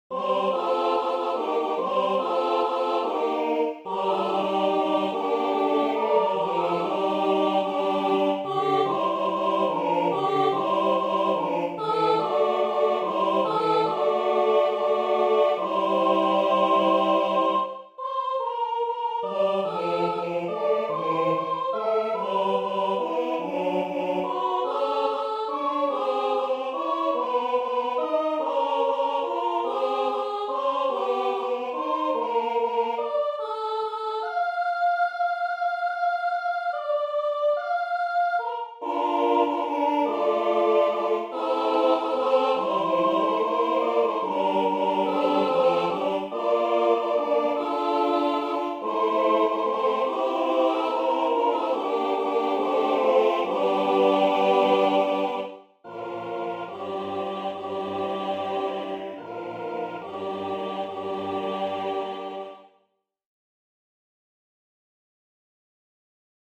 Title: Schön Rohtraut Composer: Hugo Distler Lyricist: Eduard Mörike Number of voices: 3vv Voicing: SAT (B optional) Genre: Secular, Lied
Language: German Instruments: A cappella
First published: 1939 Description: Humorous song in 4 verses.